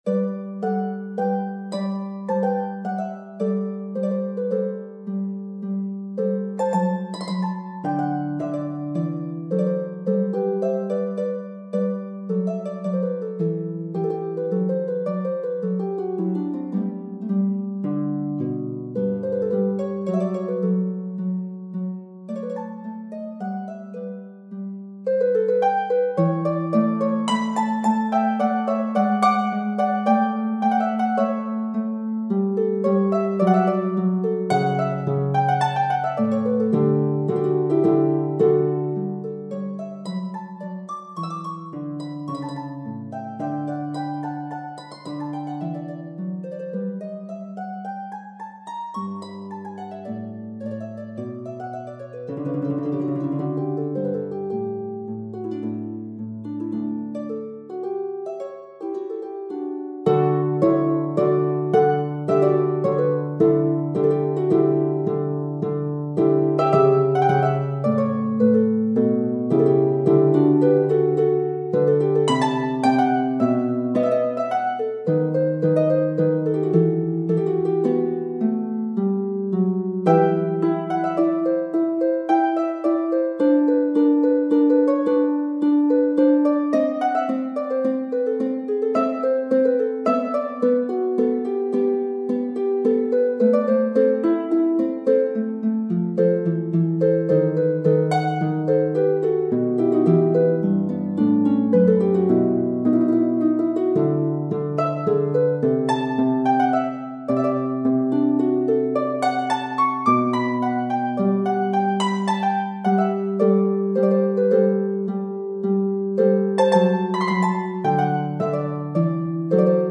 Key: G major
Time Signature: 3/4
Tempo Marking: Adagio un poco =54
Instrument: Harp
Style: Classical